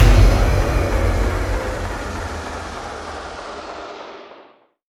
VEC3 FX Reverbkicks 25.wav